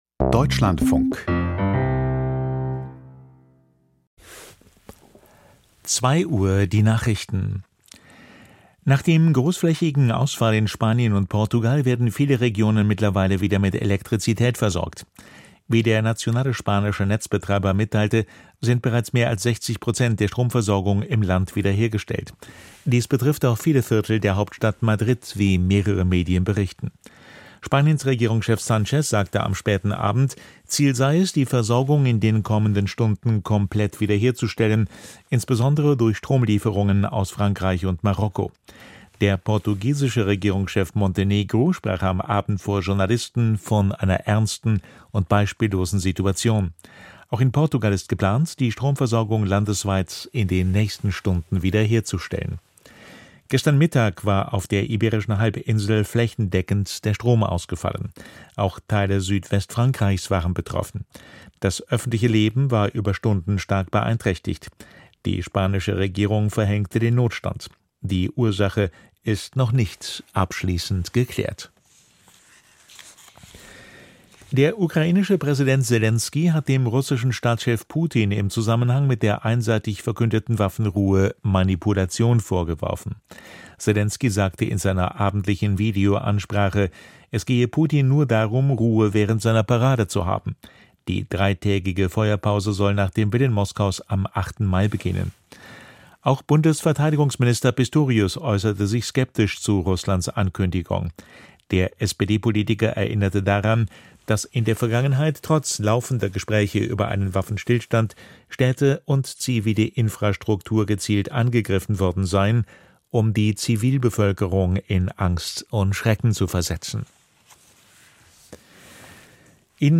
Die Deutschlandfunk-Nachrichten vom 29.04.2025, 02:00 Uhr